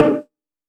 037 LoBongo LoFi.wav